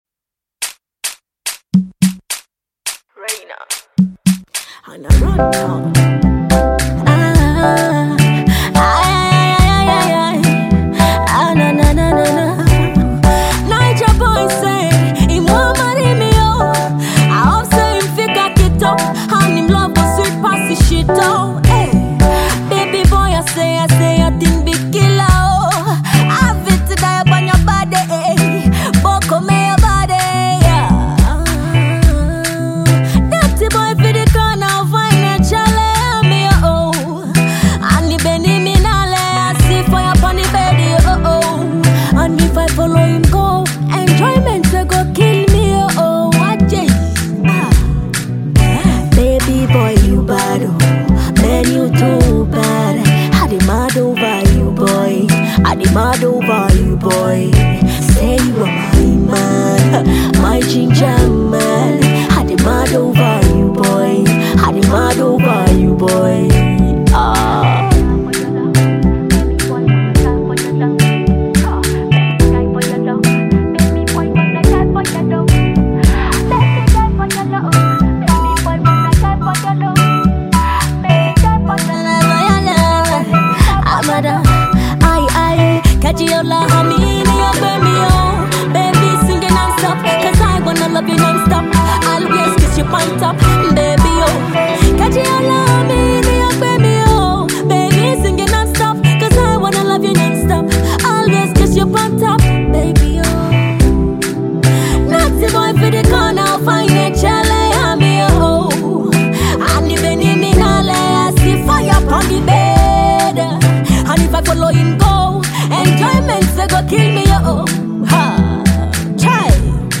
dancehall songstress